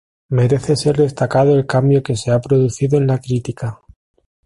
pro‧du‧ci‧do
/pɾoduˈθido/